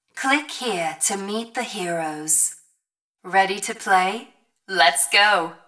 RobotNarrator.wav